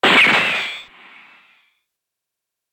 【効果音】拳銃の装填音「カチャ」
ドラマや映画でよく拳銃を構える時に鳴る「カチャ」という効果音素材です。...